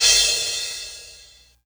• Crash Sound Sample F Key 13.wav
Royality free crash cymbal sample tuned to the F note. Loudest frequency: 6071Hz
crash-sound-sample-f-key-13-A1U.wav